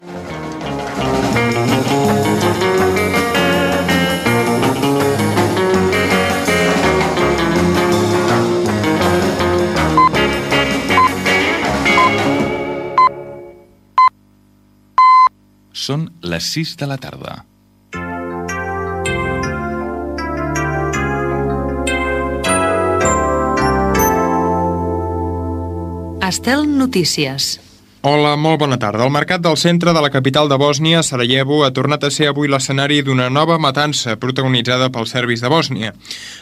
Senyals horaris, hora, sintonia de l'emissora i inici del butlletí: guerra de Bòsnia.
Informatiu